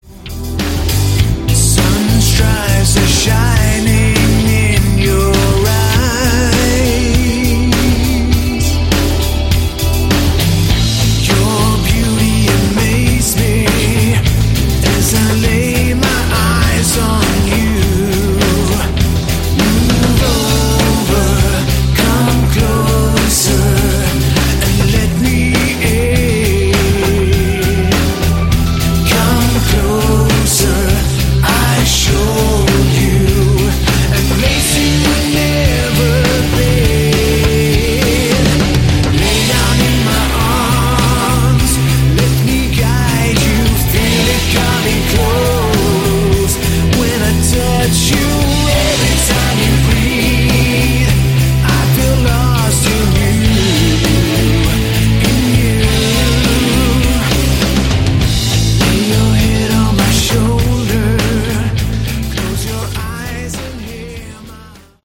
Category: Scandi AOR
Smooth vocals, choruses, lots of keys and subtle guitars.